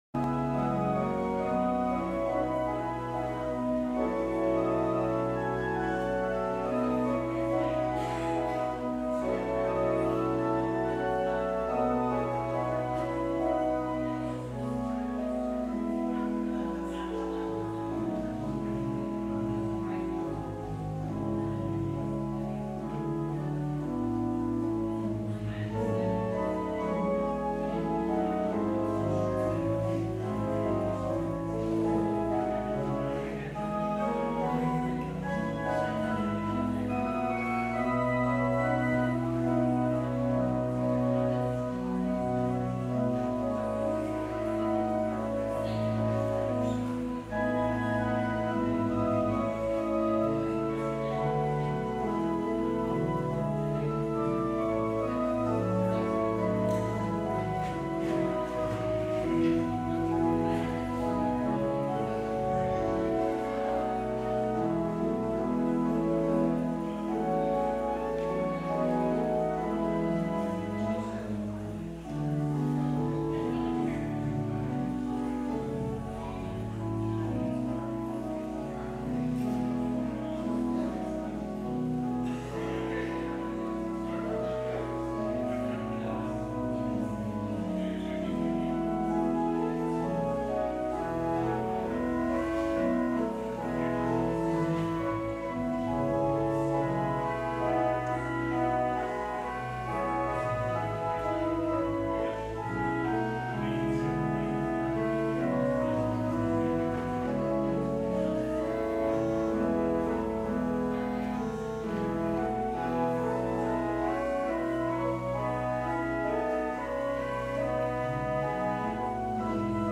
Traditional Sermon